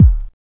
MELLOW RING.wav